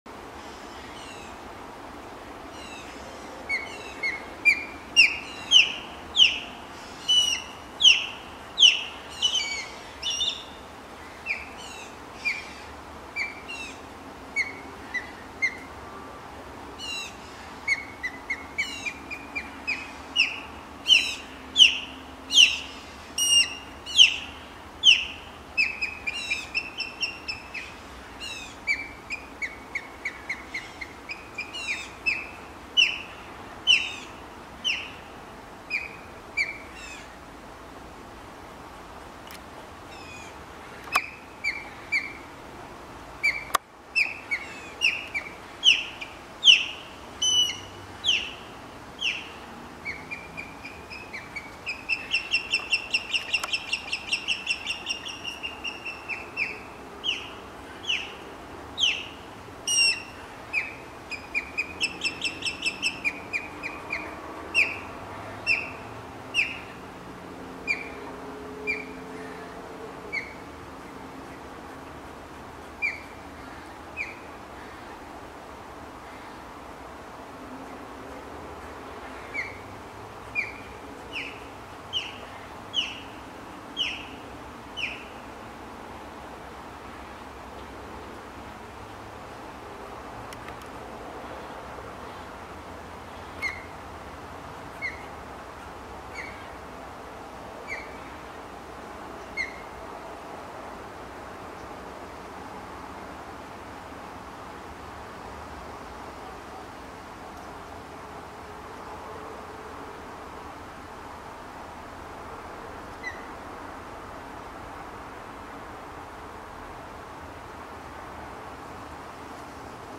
There’s a marked channel to go ashore and daymark ‘YL’ is home to an osprey nest.
Osprey
Osprey-Calling-Loud-and-Long.mp3